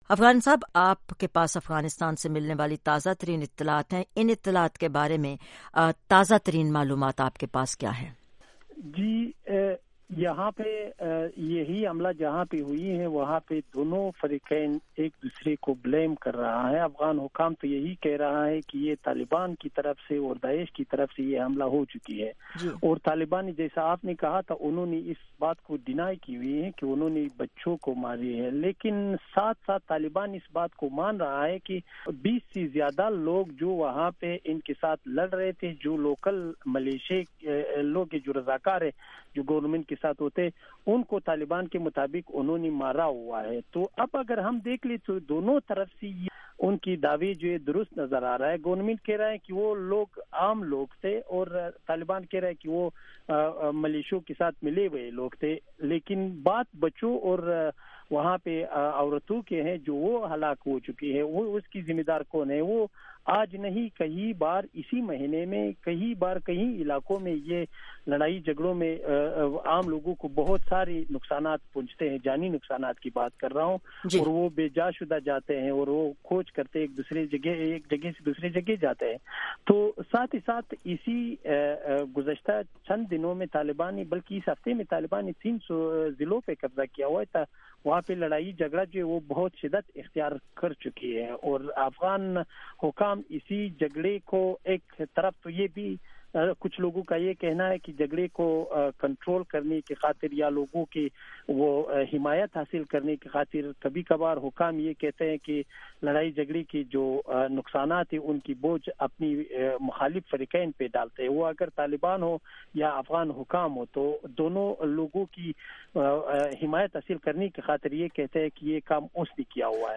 پروگرام ’جہاں رنگ‘ میں تجزیہ کاروں نے اپنے آرا کا اظہار خیال کیا۔
JR-discussion: Who is responsible for blasts in Afghanistan